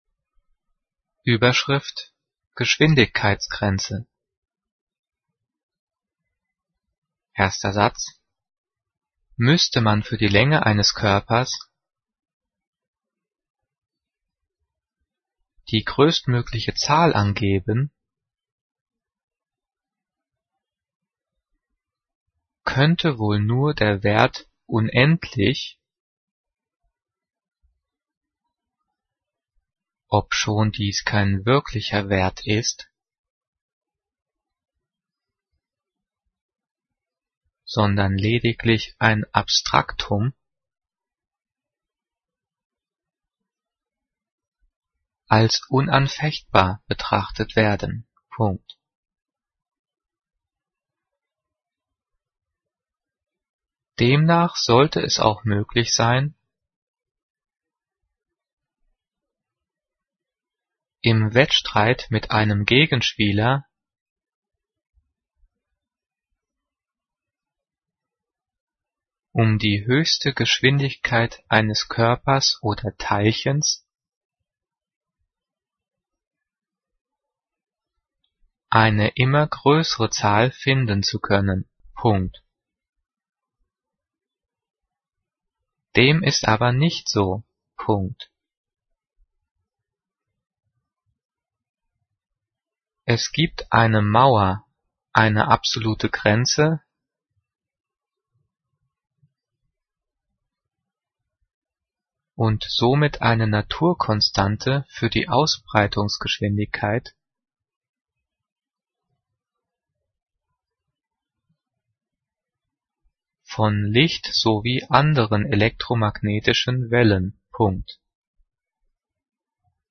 Diktat: "Geschwindigkeitsgrenze" - 9./10. Klasse - Zeichensetzung
Die vielen Sprechpausen sind dafür da, dass du die Audio-Datei pausierst, um mitzukommen.
Übrigens, die Satzzeichen werden außer beim Thema "Zeichensetzung" und den Übungsdiktaten der 9./10. Klasse mitdiktiert.
Diktiert: